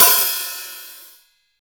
Closed Hats
hat 29.wav